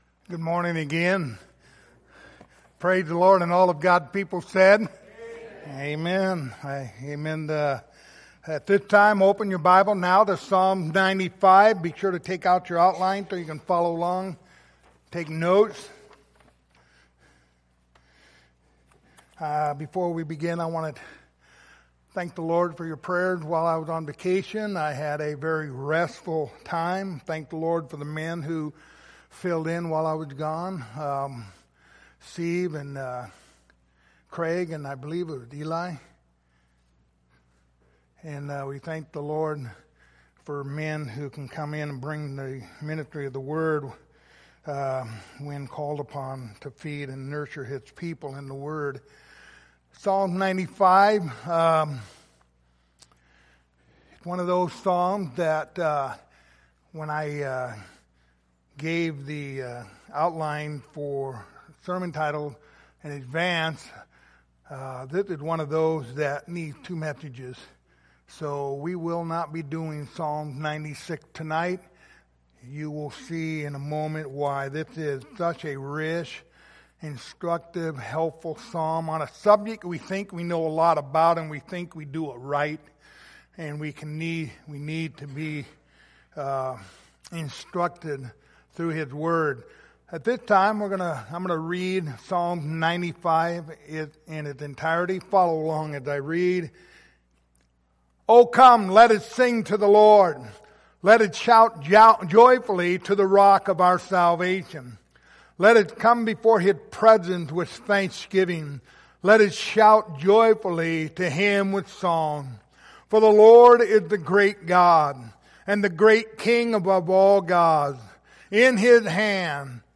Passage: Psalms 95:1-7 Service Type: Sunday Morning Topics